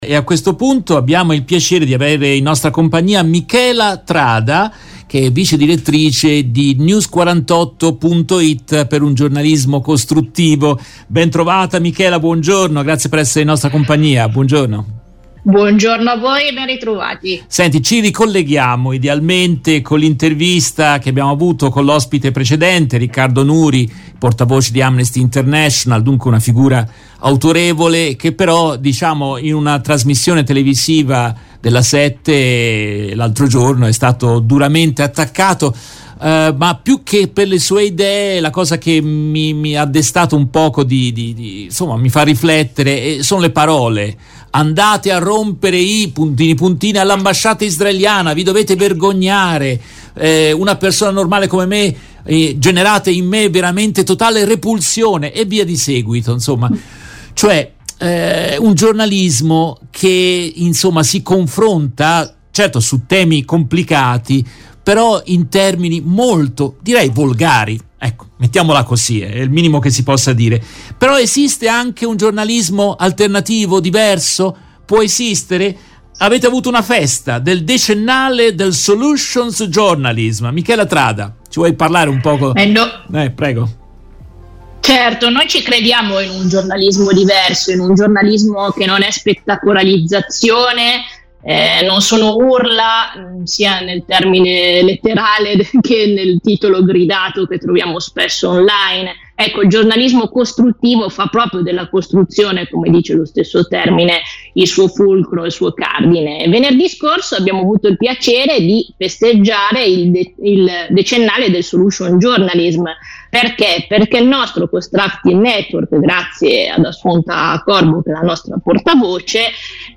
Nel corso della diretta RVS del 31 ottobre 2023